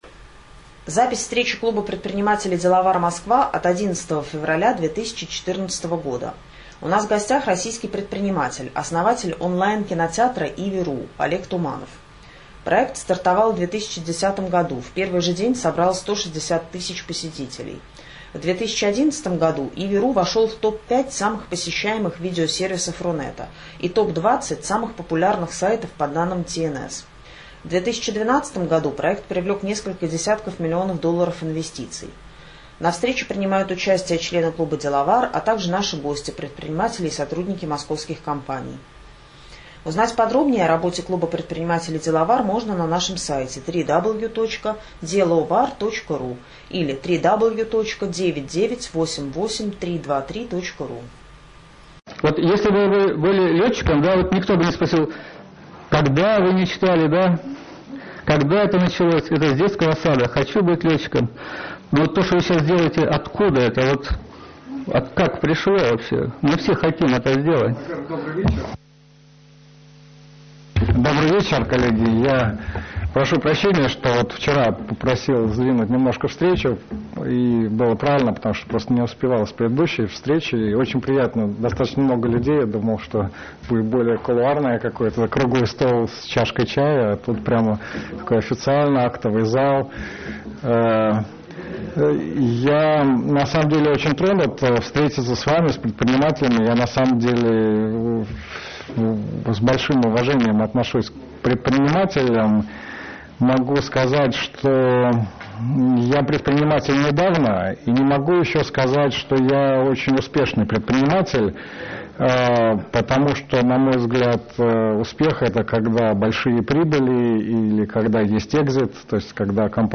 11 февраля 2014 года клуб предпринимателей Деловар г. Москва провел первую в 2014 году ОТКРЫТУЮ встречу клуба.